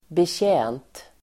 Uttal: [betj'ä:nt]